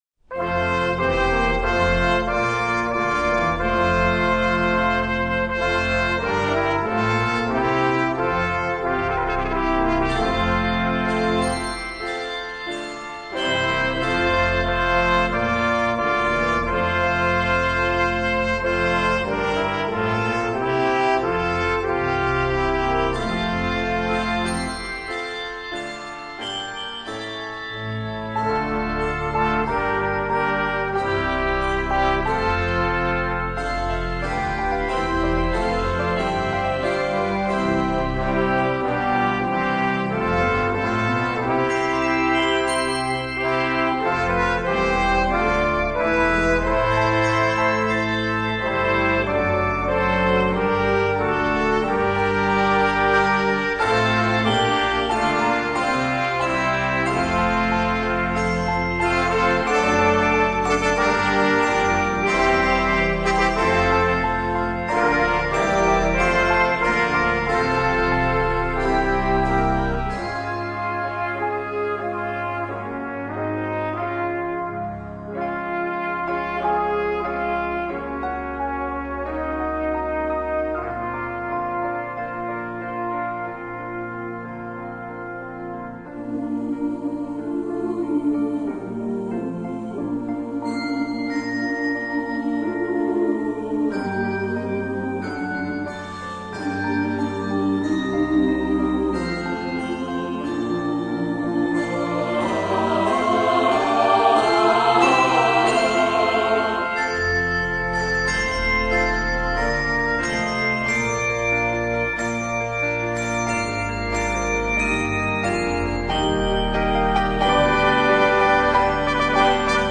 majestic hymn concertato